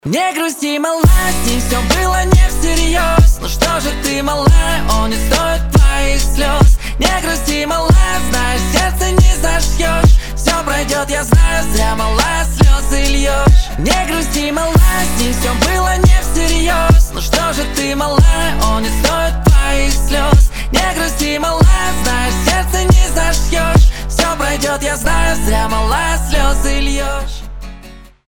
• Качество: 320, Stereo
гитара
лирика